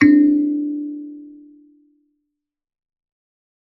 kalimba2_wood-D3-ff.wav